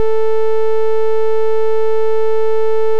OPL waveforms
Abs-sine. Waveform 2. Scaling PD variation. Zoom in 2x to remove the negative half.